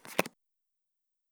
suara langkah pion & memakan pion